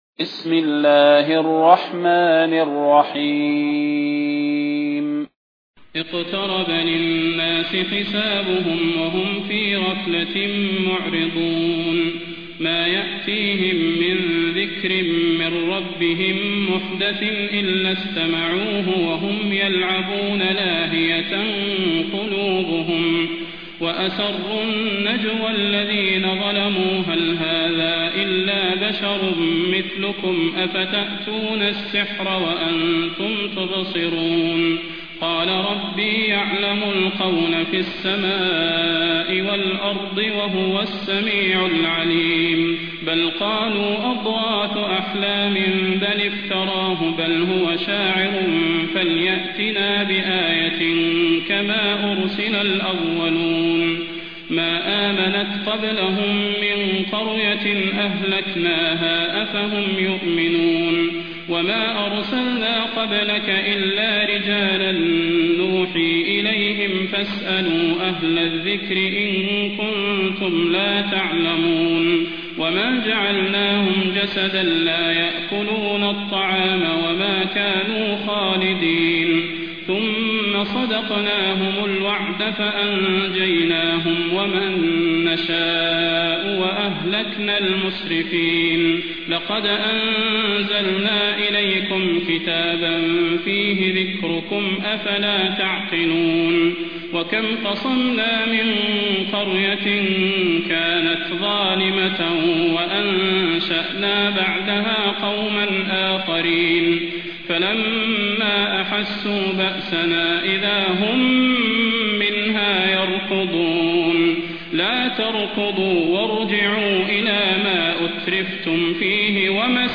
المكان: المسجد النبوي الشيخ: فضيلة الشيخ د. صلاح بن محمد البدير فضيلة الشيخ د. صلاح بن محمد البدير الأنبياء